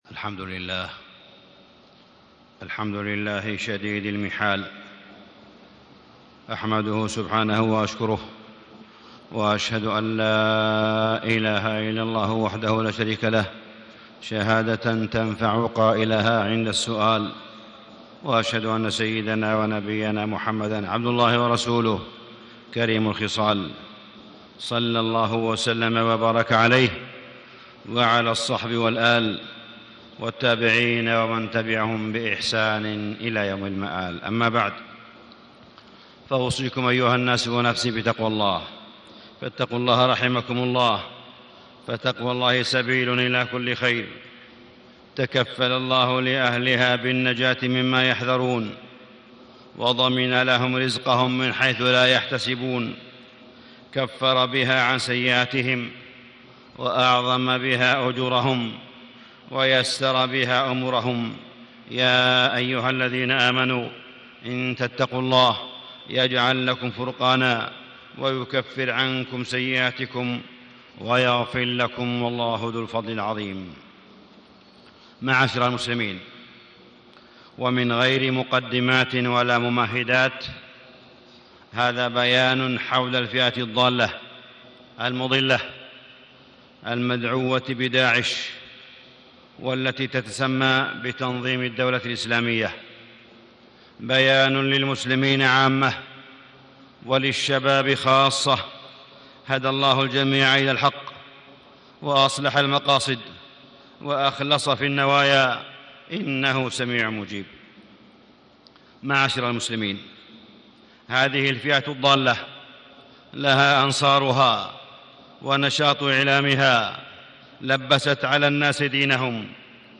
تاريخ النشر ٩ شوال ١٤٣٦ هـ المكان: المسجد الحرام الشيخ: معالي الشيخ أ.د. صالح بن عبدالله بن حميد معالي الشيخ أ.د. صالح بن عبدالله بن حميد بيان حول الفئة الضالة داعش